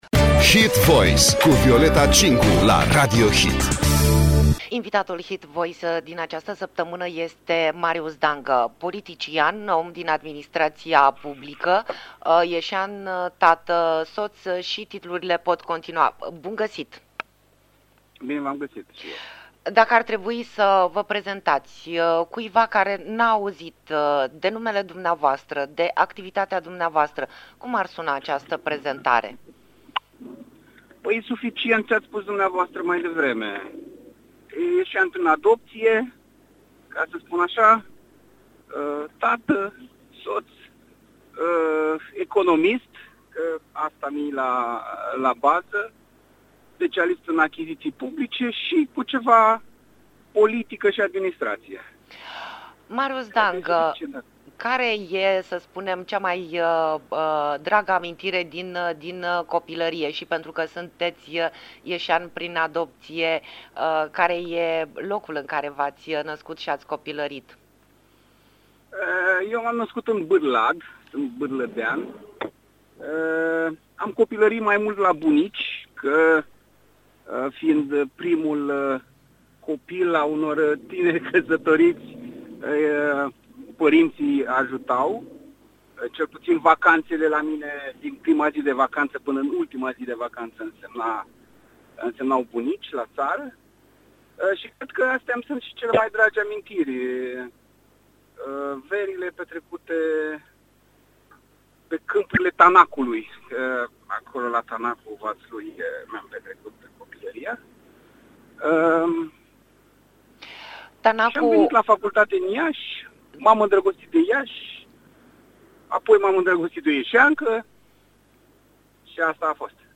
Interviu cu politicianul liberal Marius Dangă.